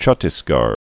(chŭtĭs-gär)